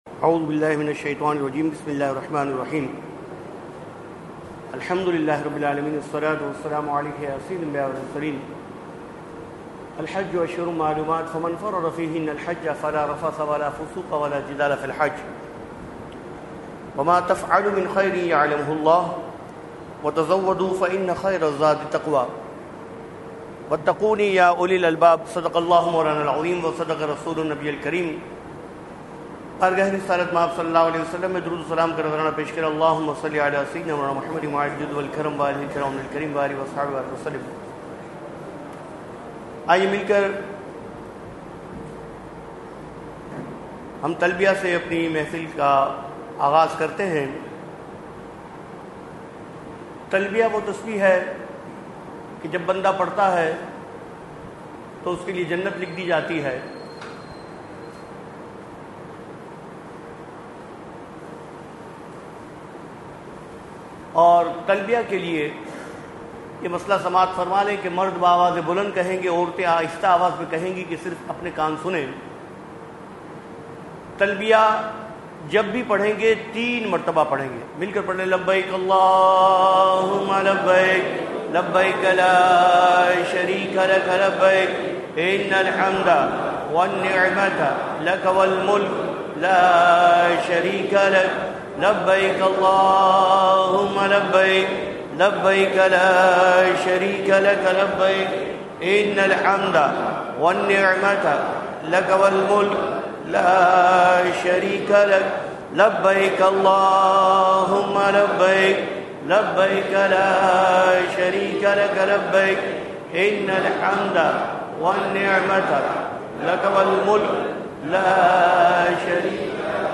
Hajj Class 2023 ~ Noor Masjid | Jamiat E Ishaat E Ahlesunnat Pakistan